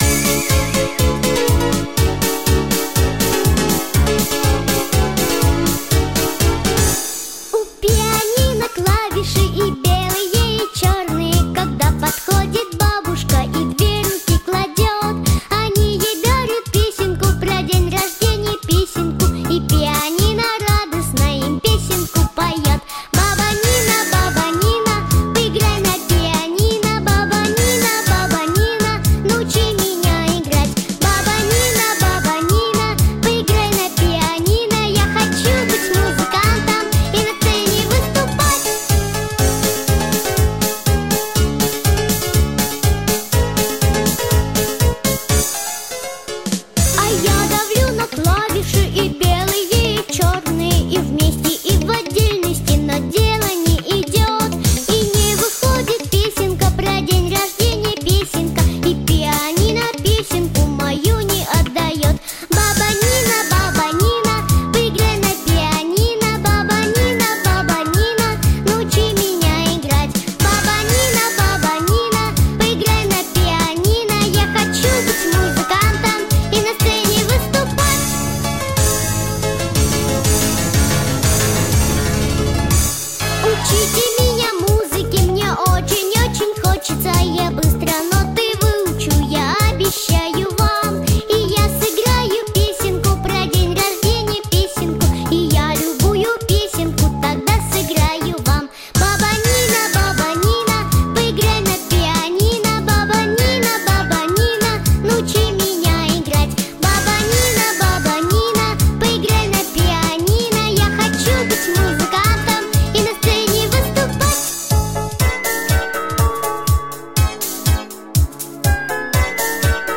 • Категория: Детские песни / Песни про бабушку